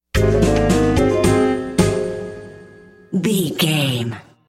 Aeolian/Minor
C#
percussion
flute
bass guitar
silly
circus
goofy
comical
cheerful
perky
Light hearted
quirky